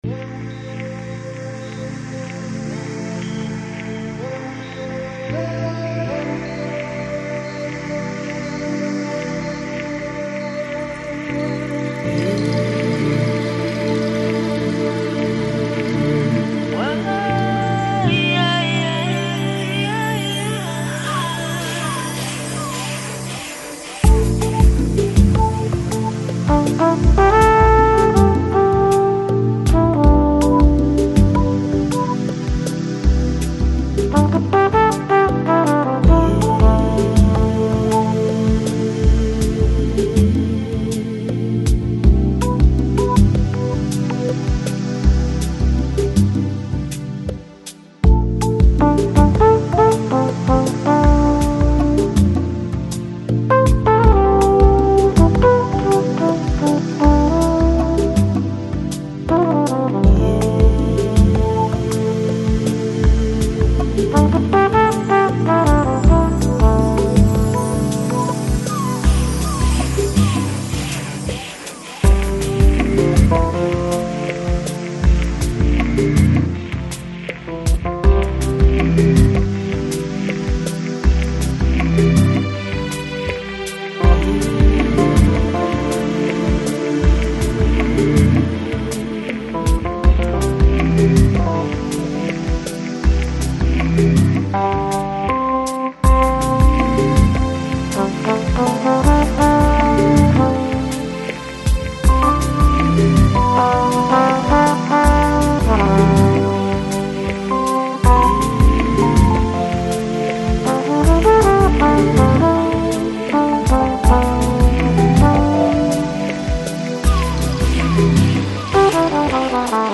Жанр: Lo-Fi, Lounge, Chillout